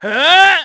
Theo-Vox_Skill1.wav